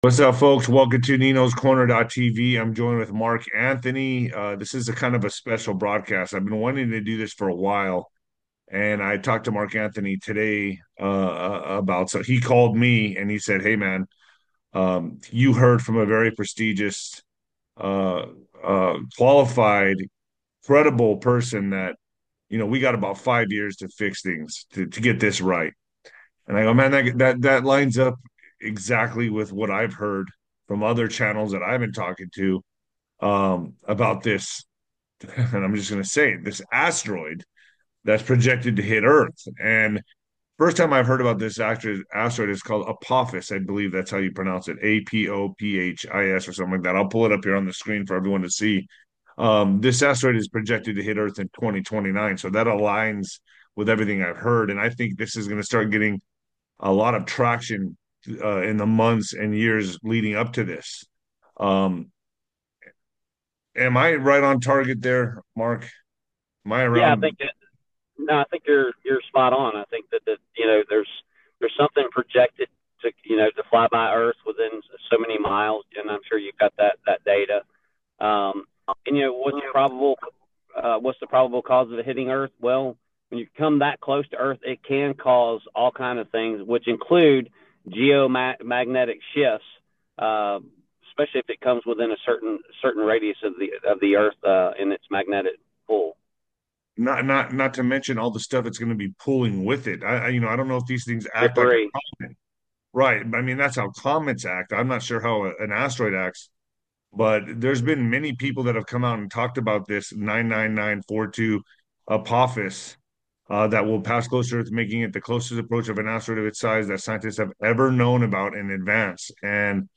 The conversation also touches on the need for spiritual readiness and community involvement.